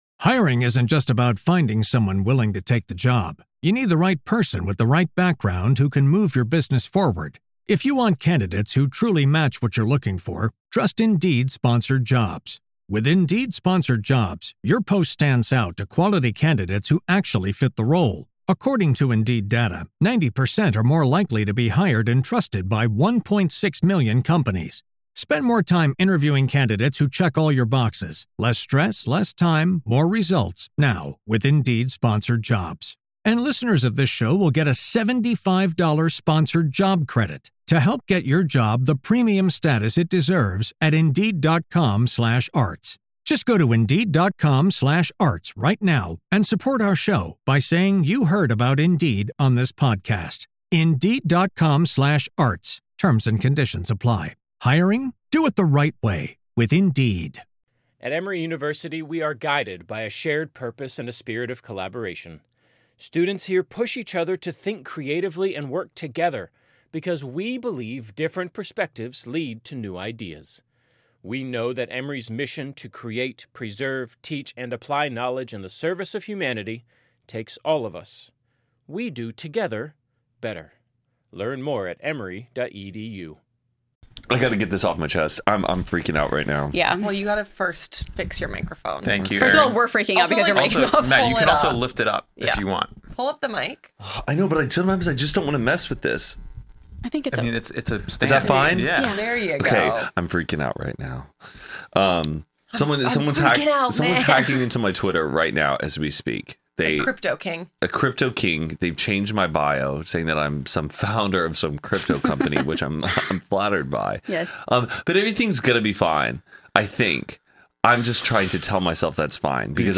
We hope you enjoy this conversation about Thanksgiving.